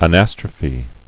[a·nas·tro·phe]